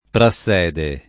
[ pra SS$ de ]